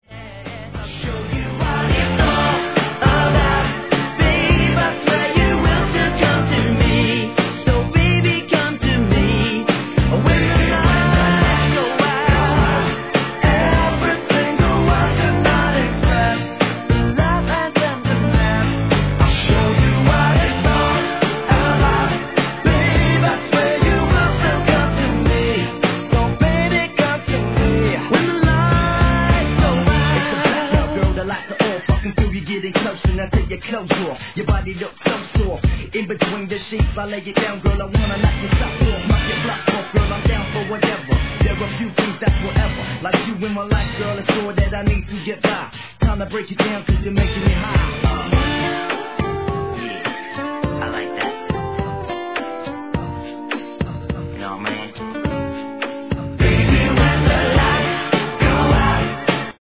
HIP HOP/R&B
分かりやすいプロダクションは万人受け!!